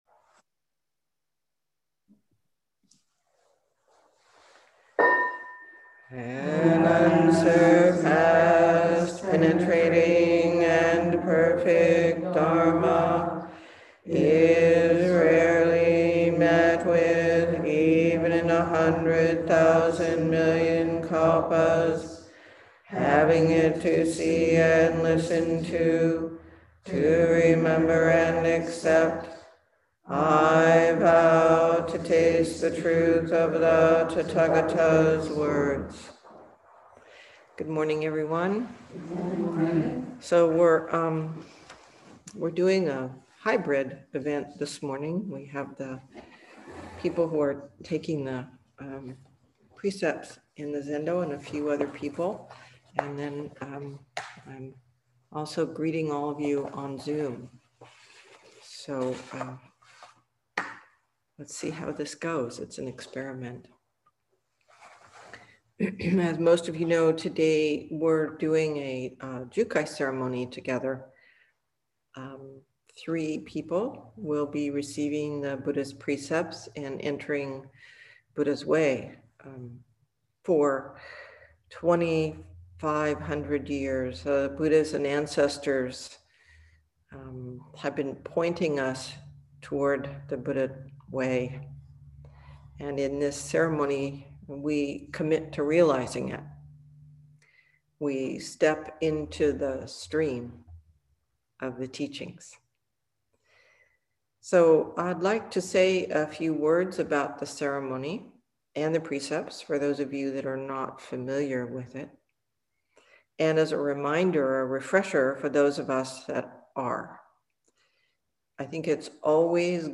This is the introductory talk to the Jukai or Bodhisattva Precepts Ceremony.